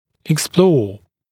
[ɪk’splɔː] [ek-][ик’спло:] [эк-]исследовать, изучать, анализировать